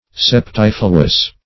Search Result for " septifluous" : The Collaborative International Dictionary of English v.0.48: Septifluous \Sep*tif"lu*ous\, a. [CF. Septemfluous .]